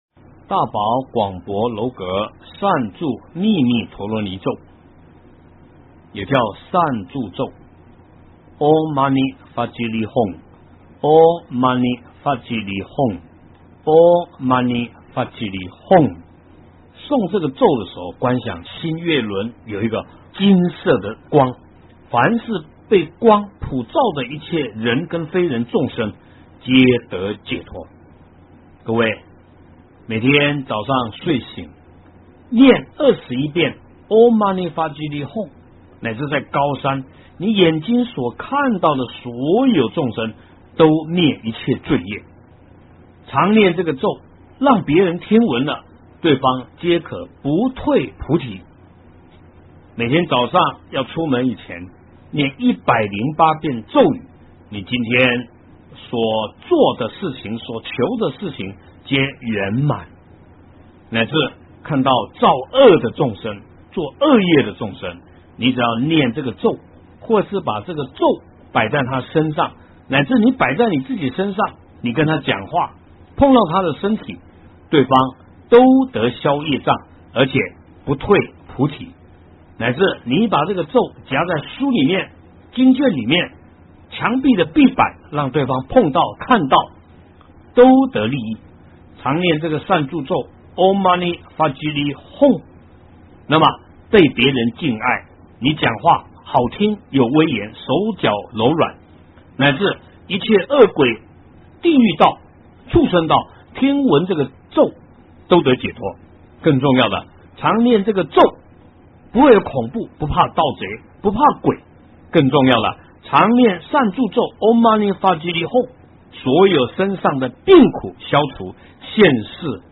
标签: 佛音 诵经 佛教音乐